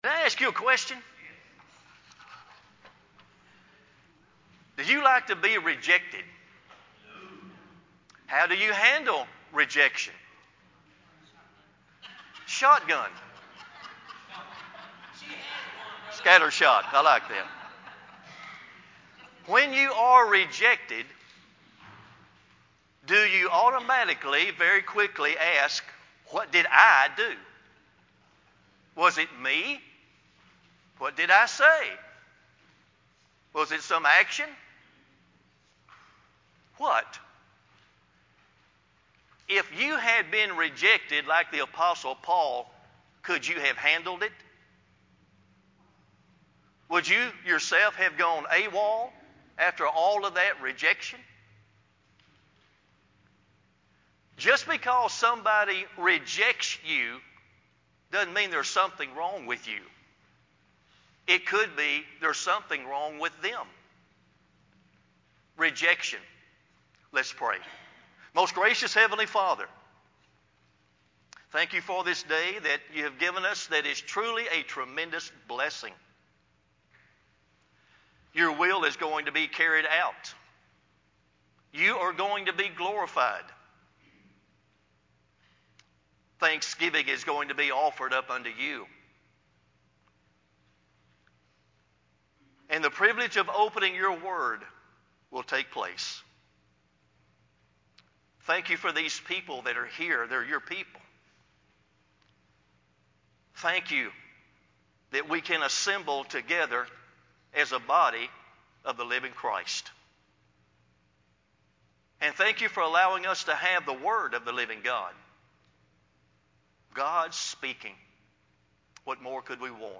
sermon-920-CD.mp3